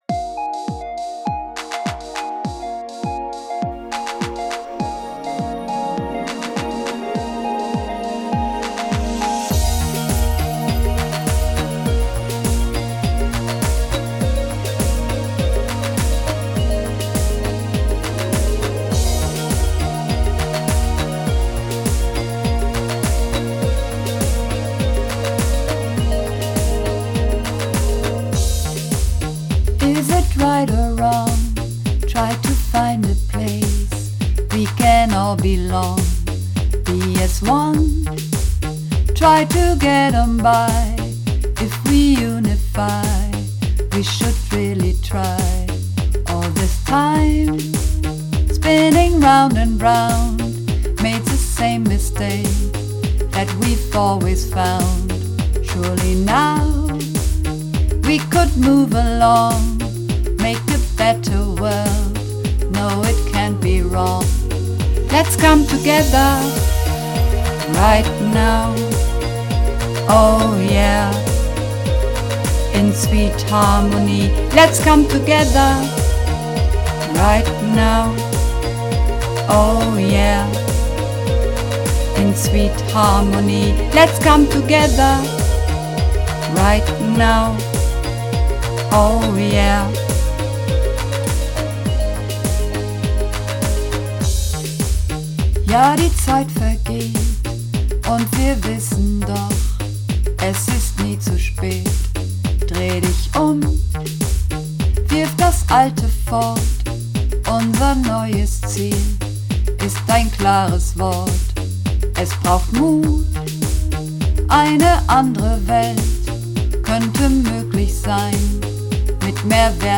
Übungsaufnahmen - Sweet Harmony
Sweet Harmony (Bass)